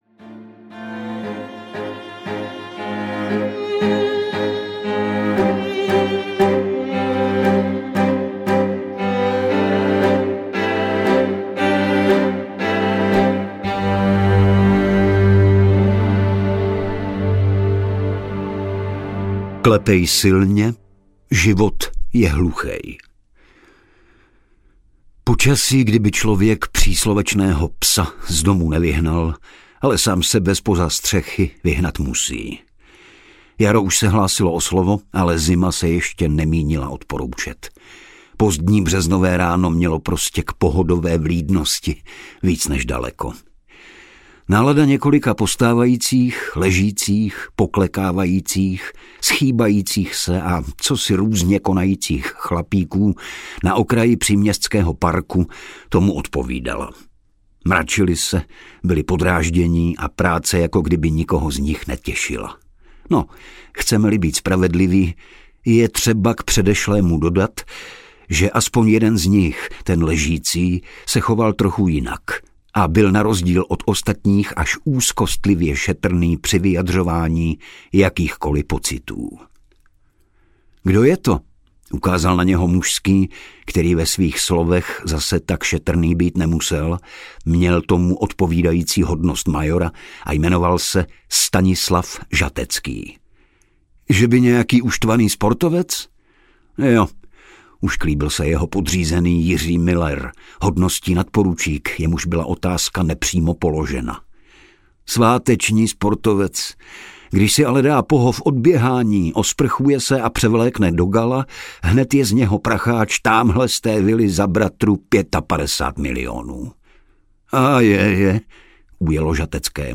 Doteky hříchu audiokniha
Ukázka z knihy